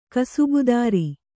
♪ kasubudāri